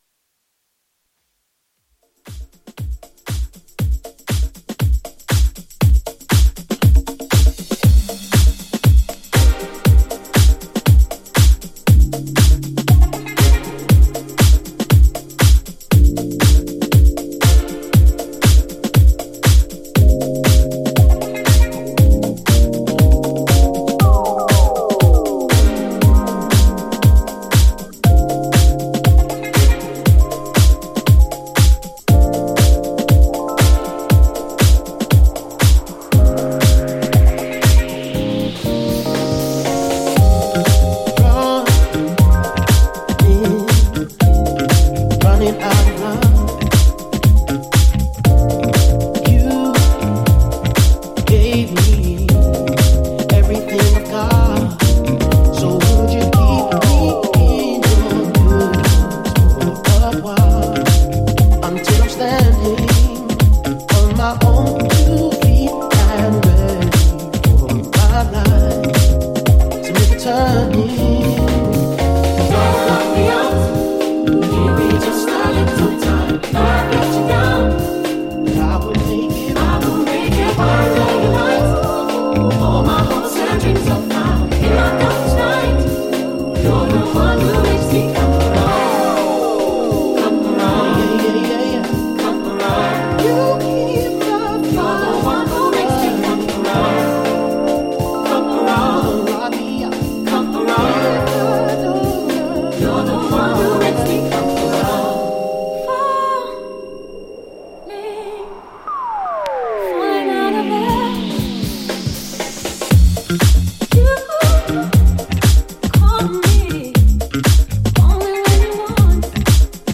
ジャンル(スタイル) NU DISCO / DISCO HOUSE / SOULFUL HOUSE